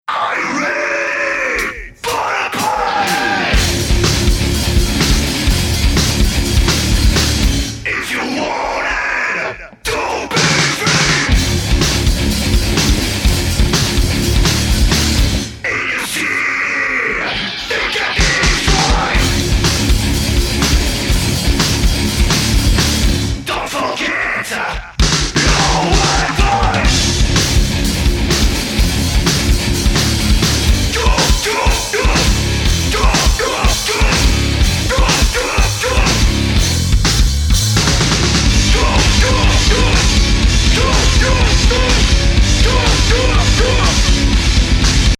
et voici l'extrait N°2 après le traitement sur la voix :mrgreen: :??hoo
j'ai doublé la piste de la voix (unique) plusieurs fois et fait de divers traitement sur les différentes pistes
1 : un léger pitch vers le bas (environ 30% d'un demi-ton), panoramique à gauche et tout petit delay (just quelque msec)
2 : un léger pitch vers le haut (environ 30% d'un demi-ton), panoramique à droit et tout petit delay (just quelque msec)
3: gros pitch vers le bas d'une quarte
3: gros pitch vers le bas d'une quinte
et 4 : une égalisation genre téléphone suivi d'une disto suivi d'une très très forte compression, genre écrasement totale
une petite reverbe room par ci, par là et un petit delay slap (que j'aime tant que j'en mets toujours de partout...hihi)